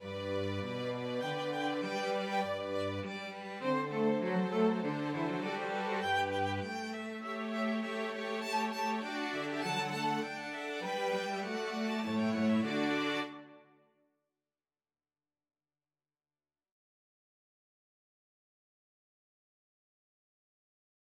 예를 들어, 볼프강 아마데우스 모차르트의 오페라 ''돈 조반니''에서는 두 개의 오케스트라가 서로 다른 박자(3/4박자와 2/4박자)로 연주하고, 나중에 3/8박자로 연주하는 세 번째 악단이 합류한다.[36]